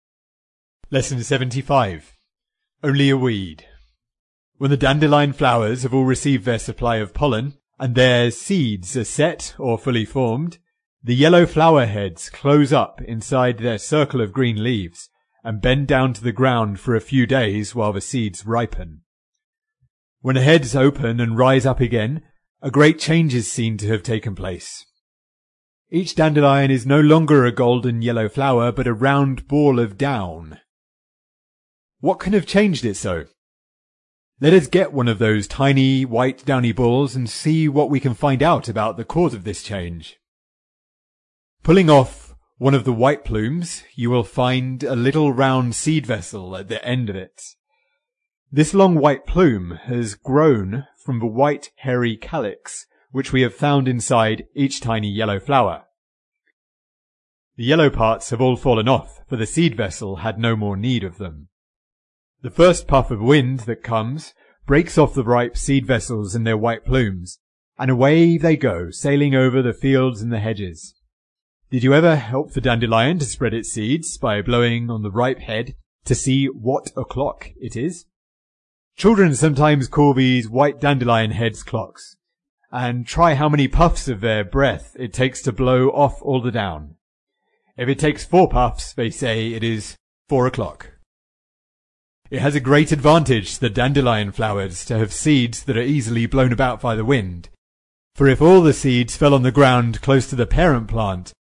在线英语听力室提供配套英文朗读与双语字幕，帮助读者全面提升英语阅读水平。